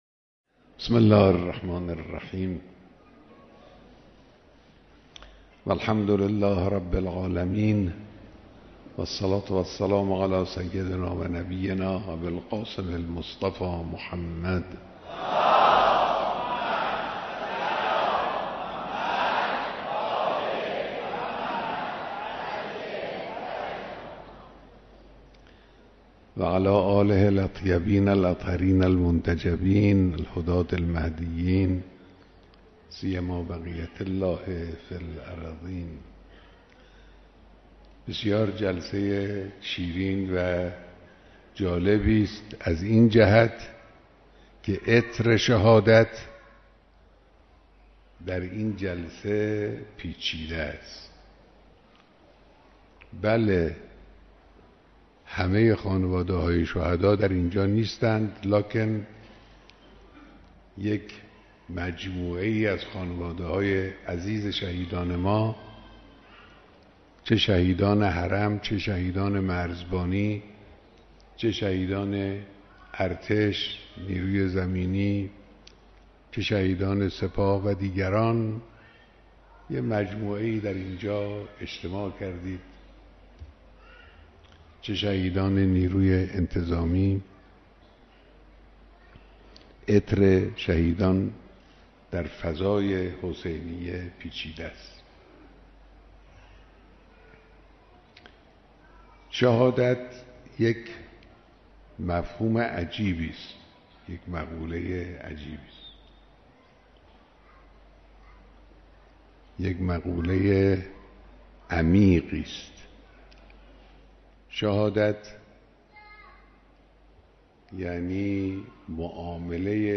دیدار خانواده‌های شهدای مرزبان و مدافع حرم با مقام معظم رهبری
به گزارش روابط عمومی رادیو معارف؛رهبر معظم انقلاب اسلامی در دیدار جمعی از خانواده های شهدای مرزبان و مدافع حرم بیانات مهمی را ایراد فرمودند كه بخشی از آن بدین شرح است.